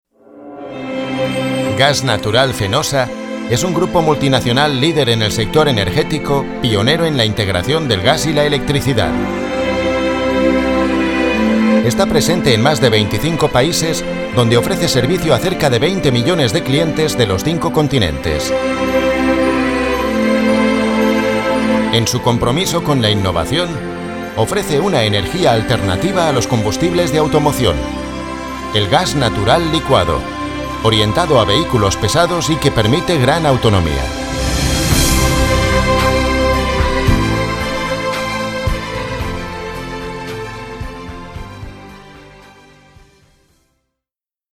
kastilisch
Sprechprobe: Industrie (Muttersprache):
I have own recording studio PRO TOOLS LE 8 and availability of travel to other studies in the area.